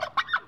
animalia_turkey_death.ogg